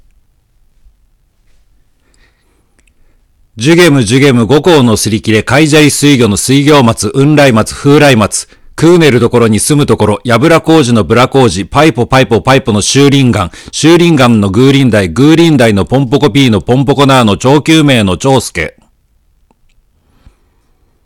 일본 원어민이 읽은 주게무의 이름